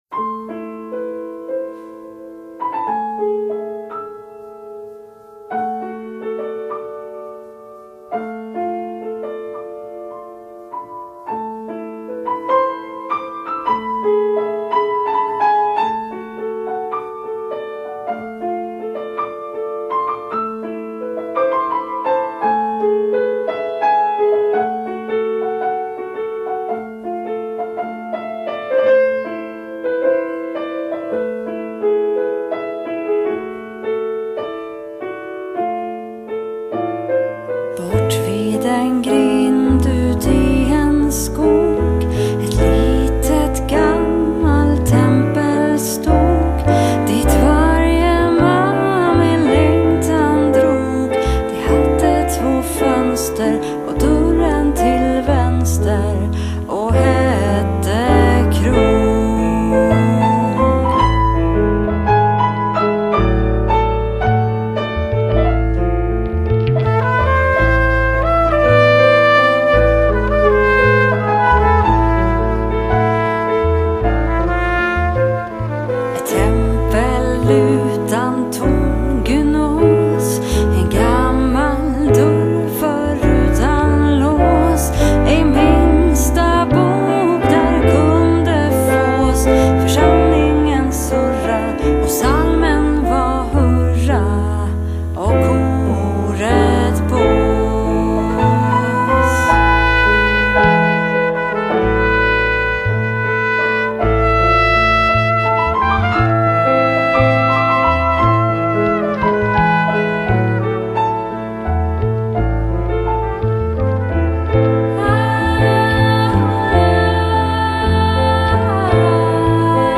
Sångerska, körledare, programledare, m.m.